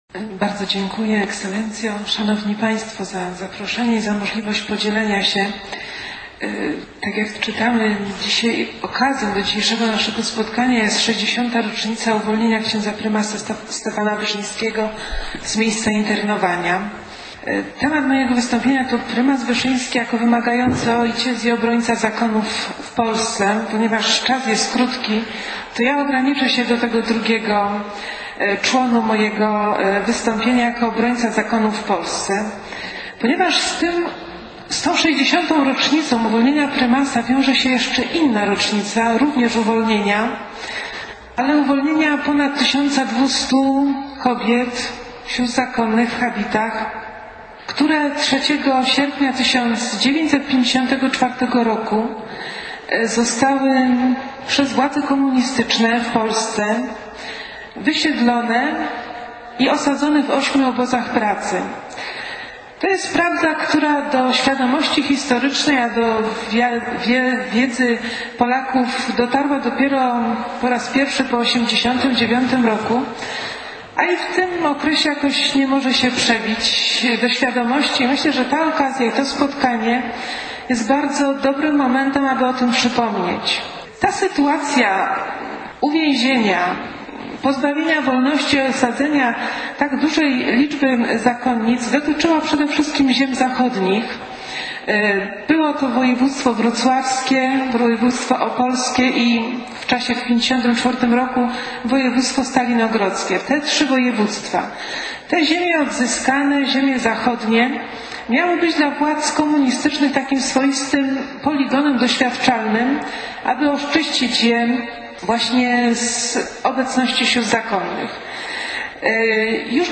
W konferencji wygłosili referaty: